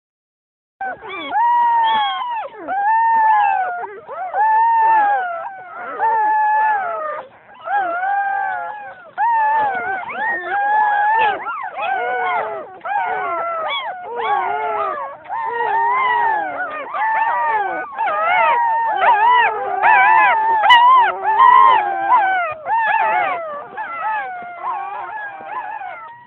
Звуки щенков
Первые звуки новорожденных щенков, которые пищат